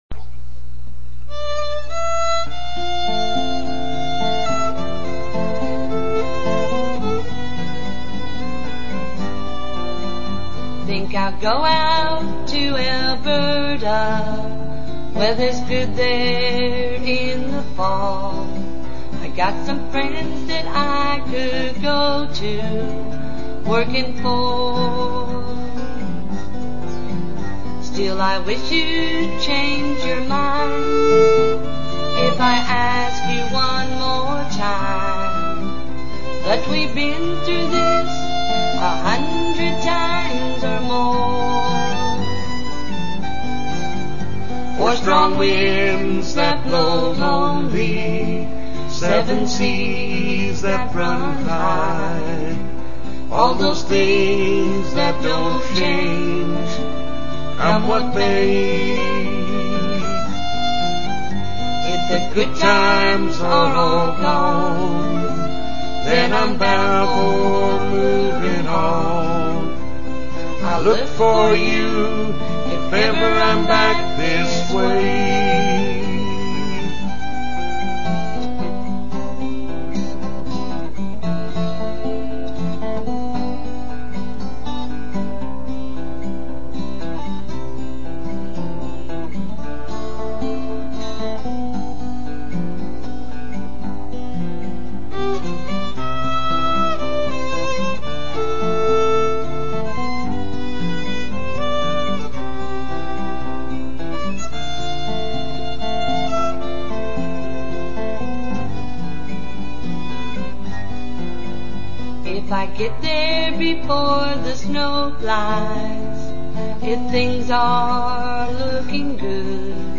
Fiddle